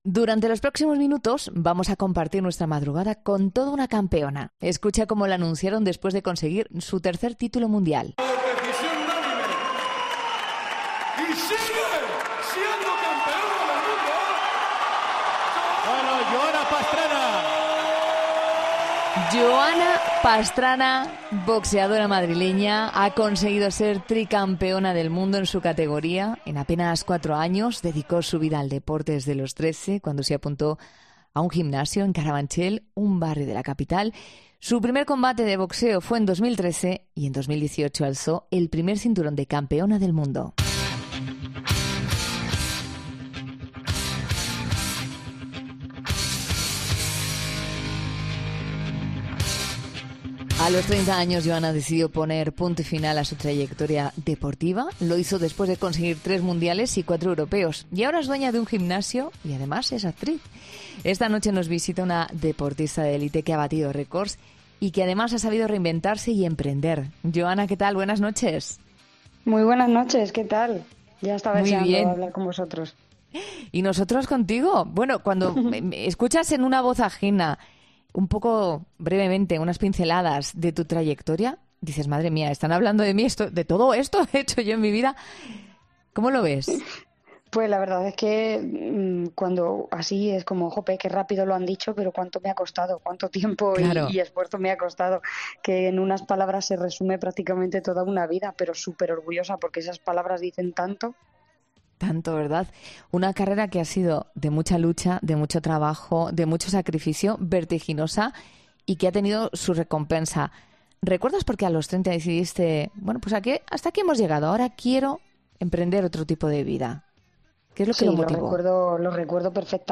La entrenadora explicó en ' La Noche de COPE ' los secretos de esta tutela.